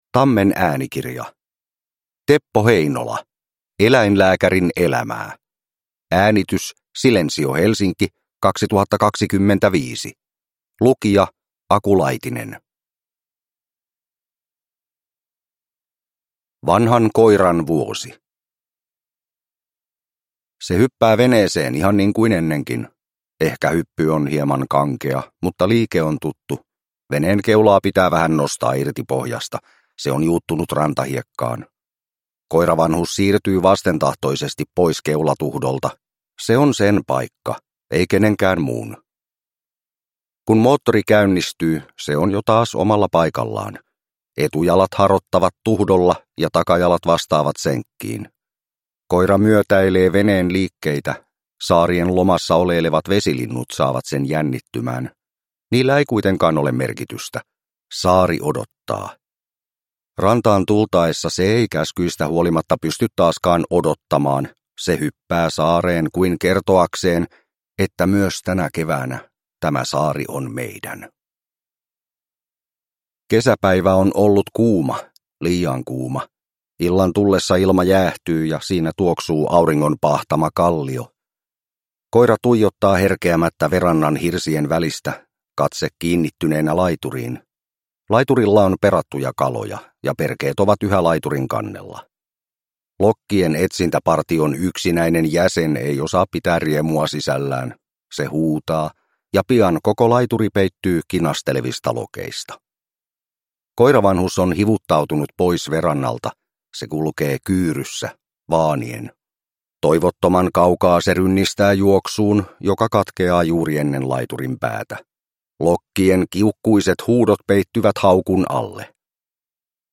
Eläinlääkärin elämää – Ljudbok